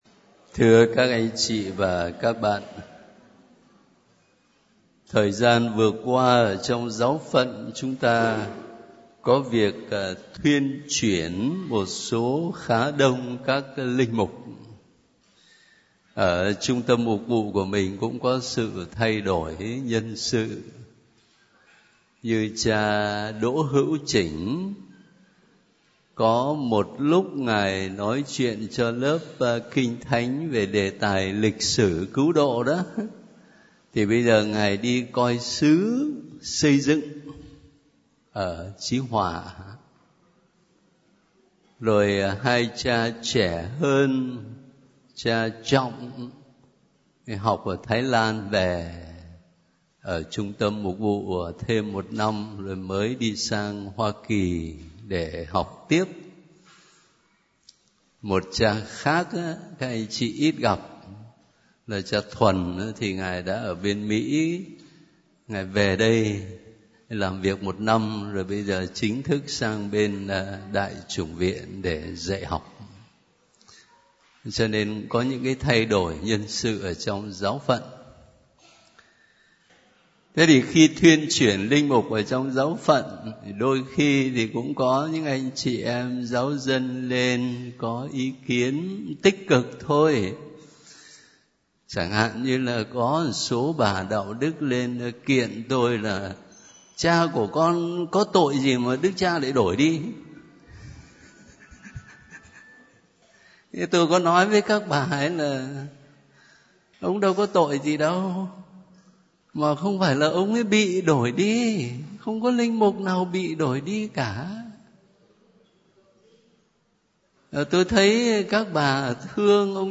Bạn đang nghe audio Chia sẻ về việc thuyên chuyển các linh mục trong Tổng Giáo Phận - Được thể hiện qua Gm. Phêrô Nguyễn Văn Khảm.